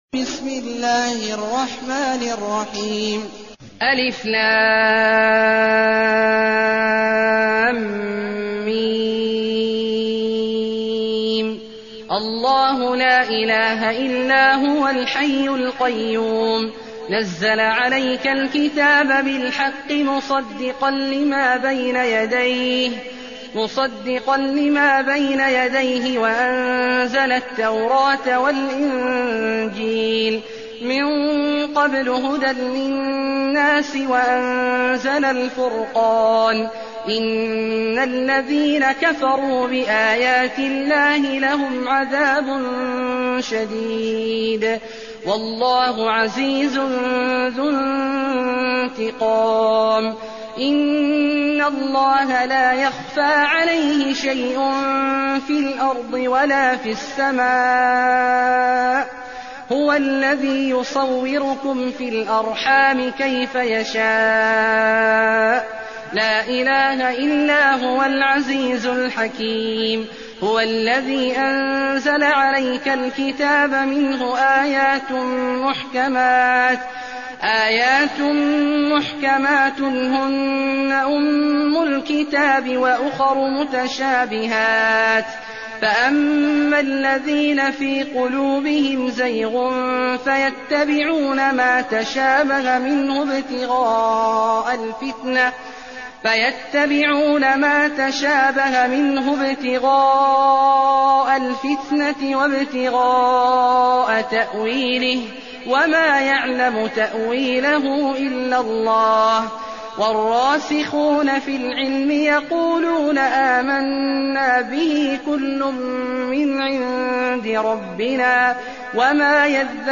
المكان: المسجد الحرام الشيخ: عبد الله عواد الجهني عبد الله عواد الجهني آل عمران